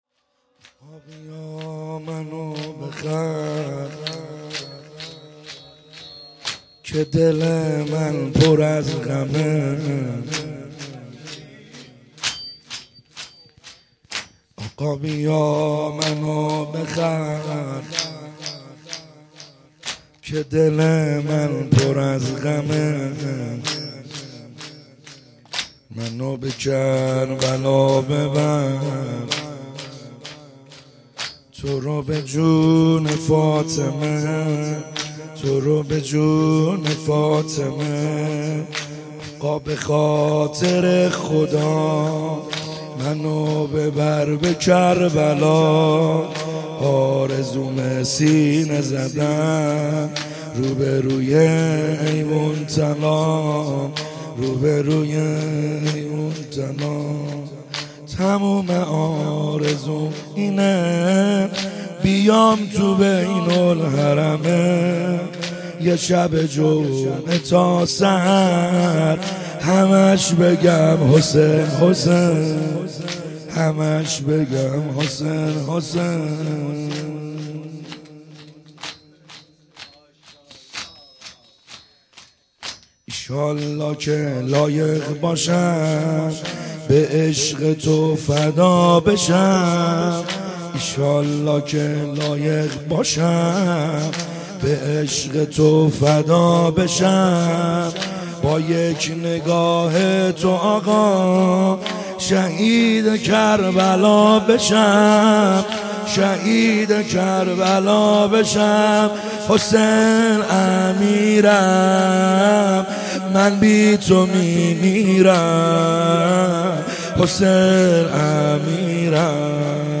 خیمه گاه - بیرق معظم محبین حضرت صاحب الزمان(عج) - شور | آقا بیا منو بخر